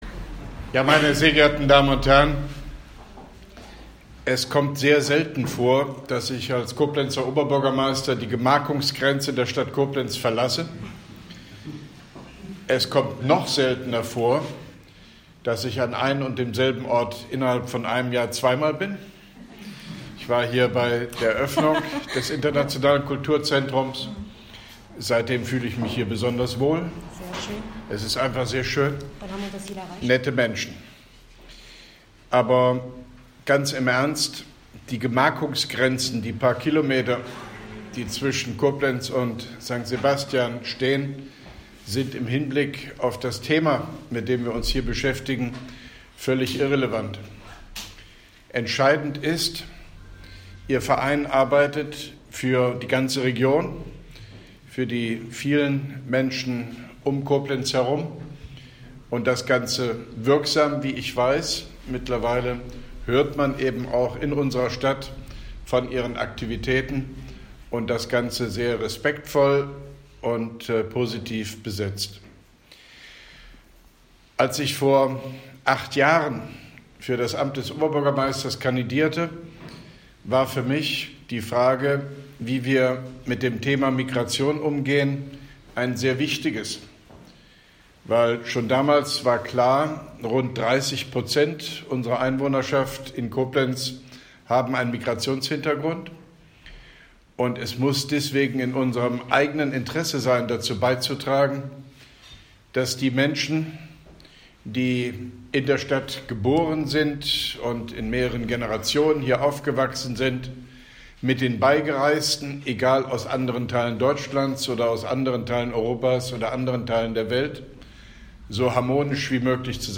Grußwort von OB Hofmann-Göttig beim Kurdischen Neujahrsfest “Newroz” des Internationalen Kulturzentrums IKZ e.V., St. Sebastian 04.04.2017